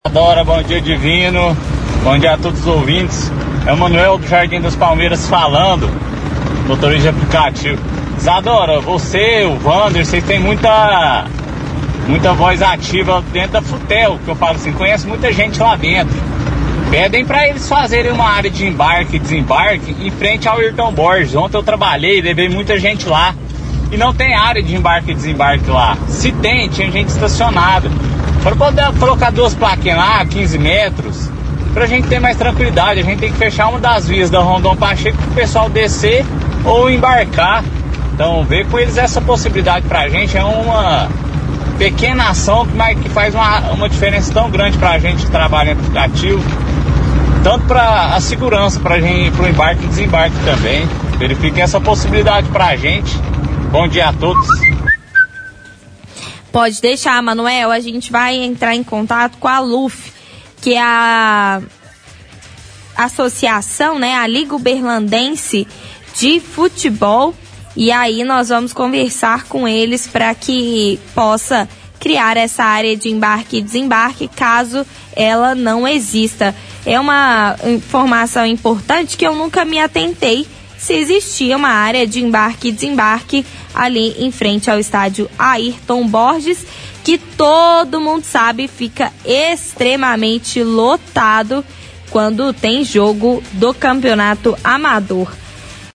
– Ouvinte motorista de aplicativo pede para que apresentadores do programa, por terem influencia, solicitem à FUTEL que façam uma área de embarque em frente ao Estádio Airton Borges.